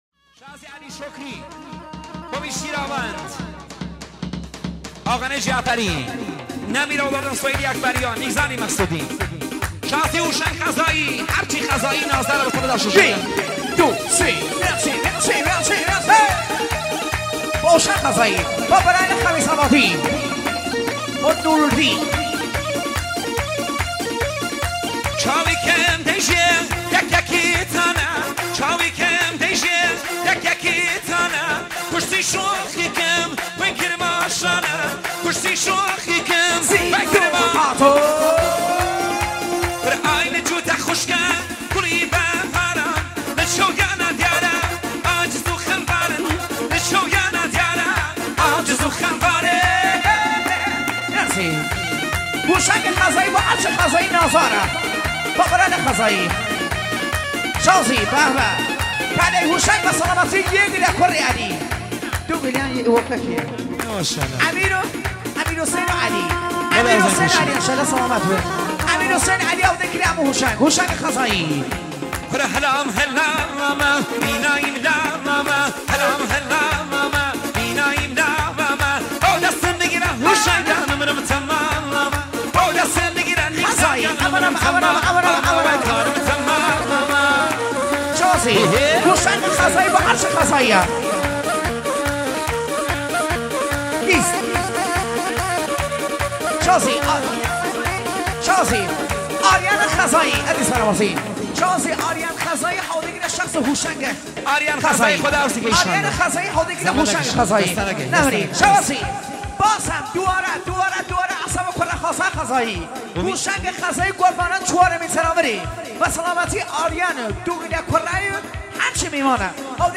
شاد ارکستری کردی
در عروسی با ارگ ارکستی